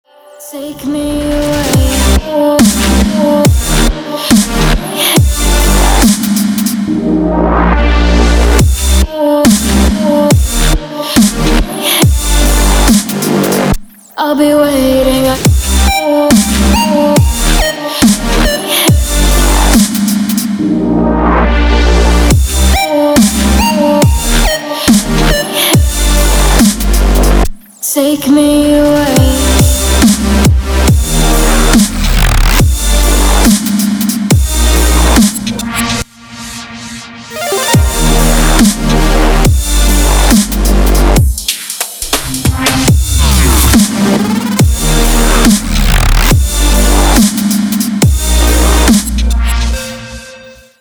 • Качество: 320, Stereo
женский вокал
club
Bass
electro
Electro Dubstep